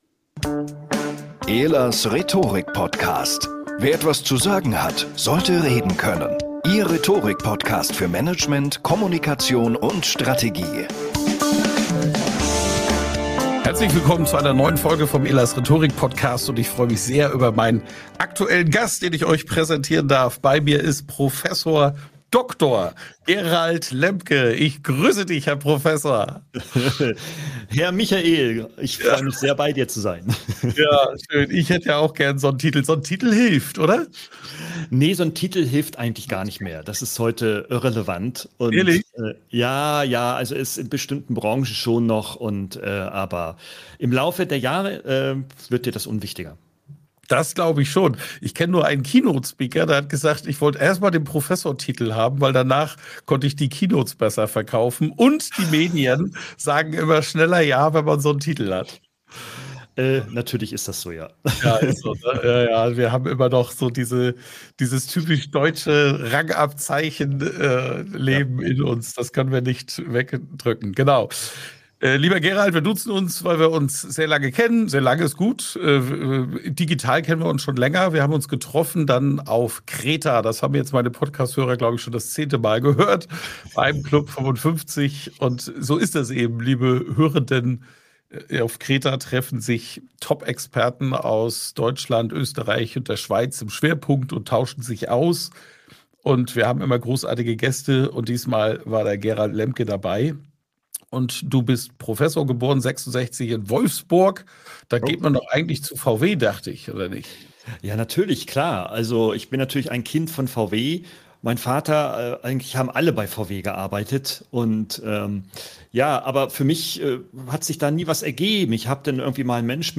Regelmäßige LIVE Talks mit den besten Expert*innen aus der DACH-Region zum Thema Rhetorik!